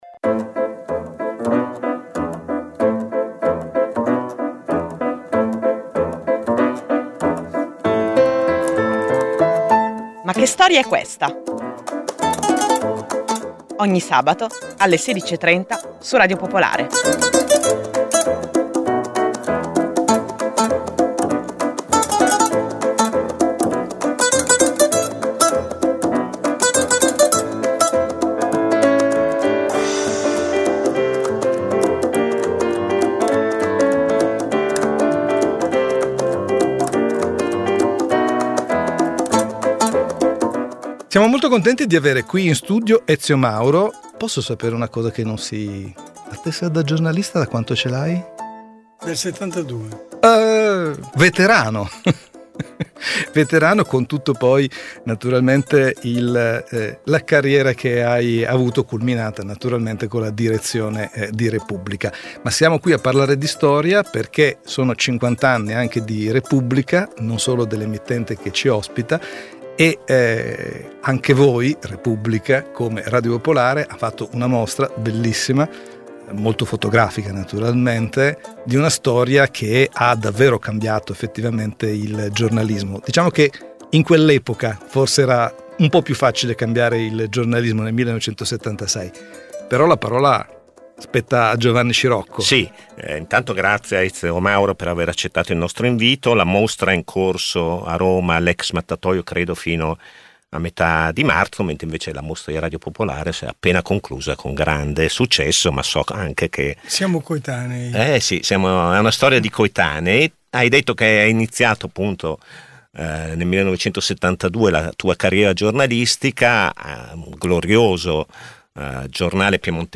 Sommario: 50 e più anni a raccontare la storia. Intervista a Ezio Mauro.